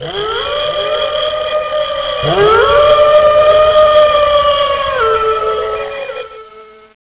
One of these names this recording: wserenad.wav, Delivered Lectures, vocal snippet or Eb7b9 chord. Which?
wserenad.wav